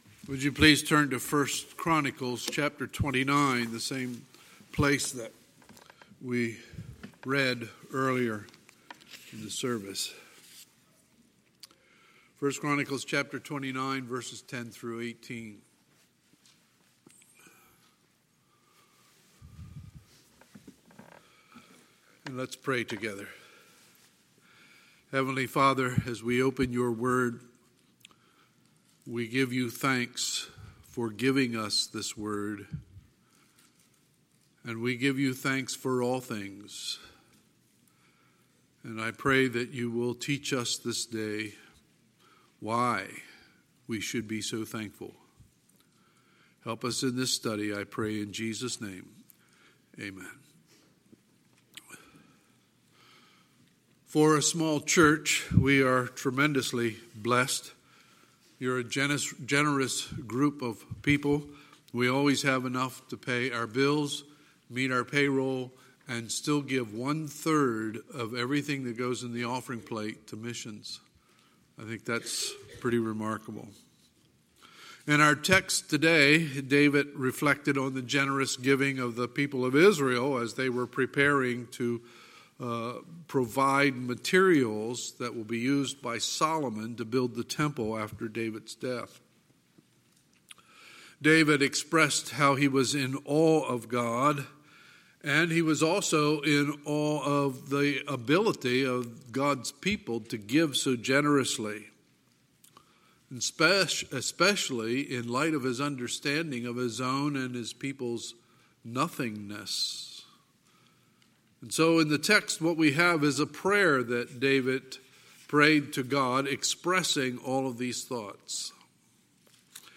Sunday, November 24, 2019 – Sunday Morning Service